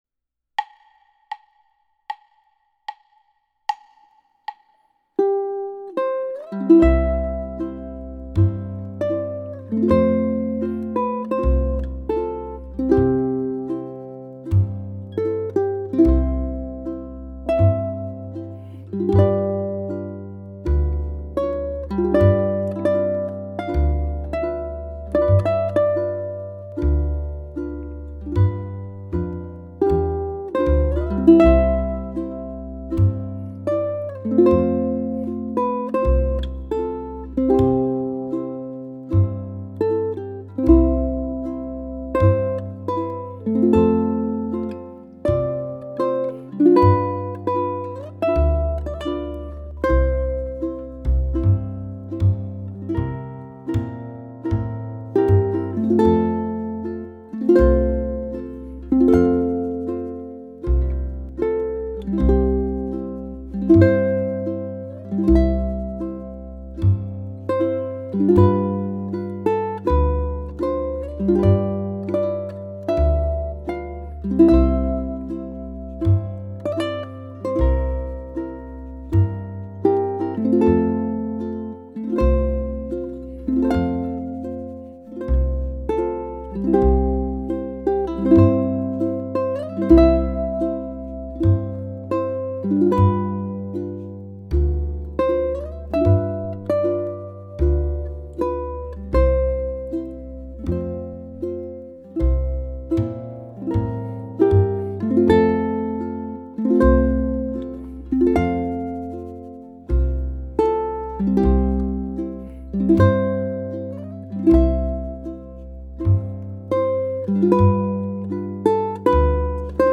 Aloha ʻOe should be performed with a slow but steady tempo—adagio or thereabouts.
ʻukulele